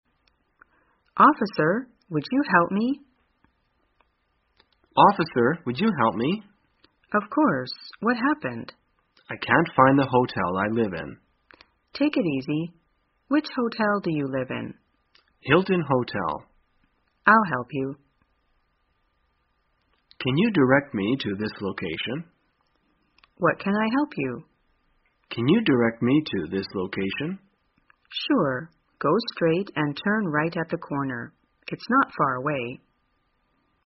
在线英语听力室生活口语天天说 第195期:怎样求助的听力文件下载,《生活口语天天说》栏目将日常生活中最常用到的口语句型进行收集和重点讲解。真人发音配字幕帮助英语爱好者们练习听力并进行口语跟读。